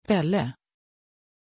Aussprache von Pelle
pronunciation-pelle-sv.mp3